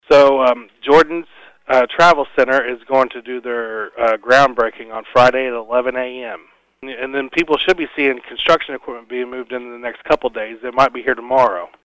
ECNN spoke with City Clerk, Brent Collins.